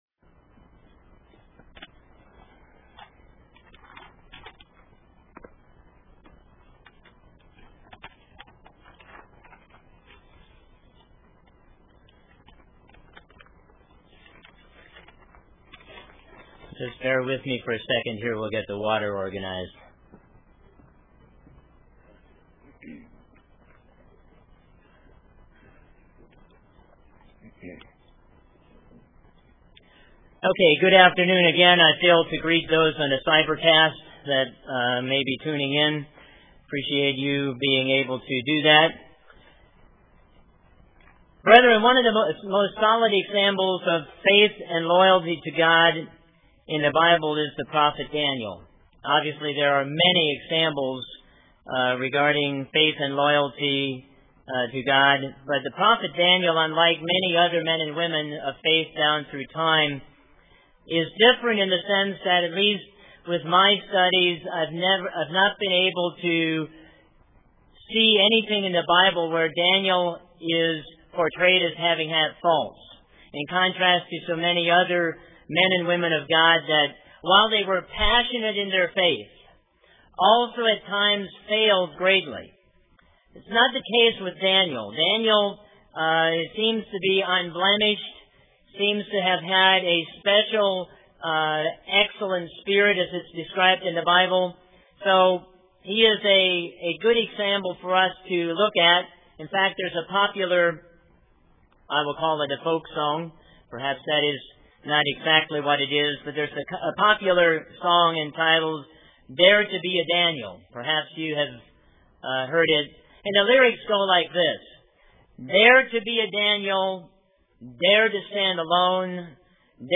Daniel's life provides a valuable example of how to conduct oneself during times of extereme crisis. This sermon addresses a number of points that we can gleam from his example and apply in our own daily lives.